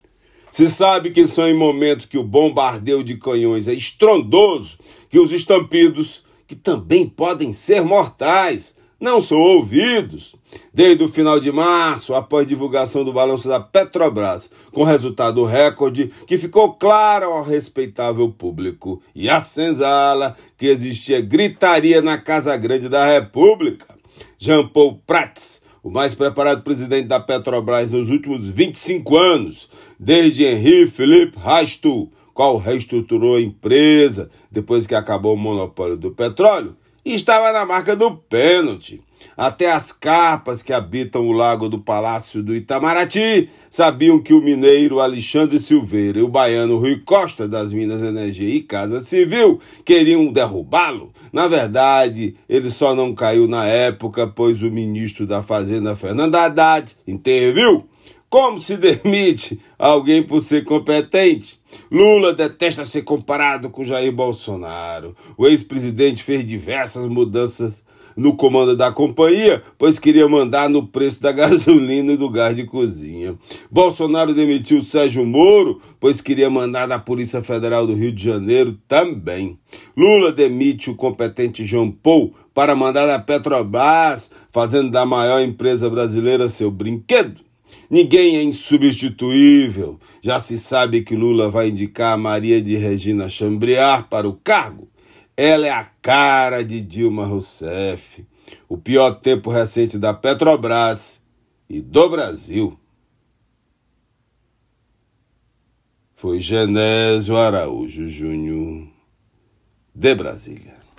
Comentário
direto de Brasília